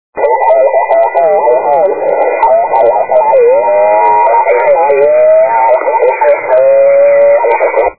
Les fichiers à télécharger sont compressés au format MP3 à 1ko/sec, ce qui explique la très médiocre qualité du son.
En cas de mauvais choix de la bande latérale, le résultat est incompréhensible :
bande 40 m dimanche 25/11/2001, émission en BLI (ou LSB) et récepteur réglé en BLS (ou USB)